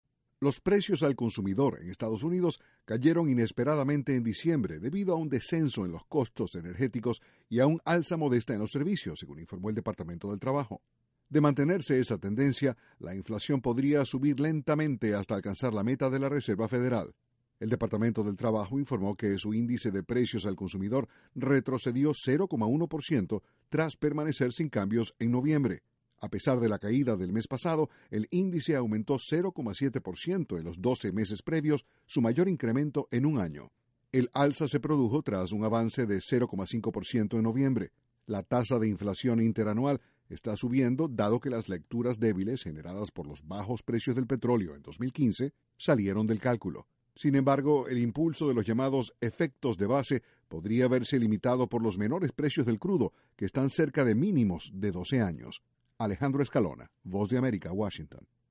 Caen precios al consumidor en Estados Unidos. Desde la Voz de América, Washington